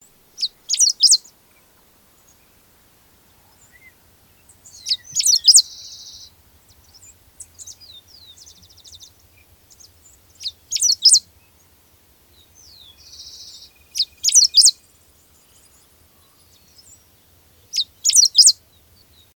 Pampa Finch (Embernagra platensis)
Life Stage: Adult
Detailed location: Camino de la Ribera del Rìo Quequèn
Condition: Wild
Certainty: Observed, Recorded vocal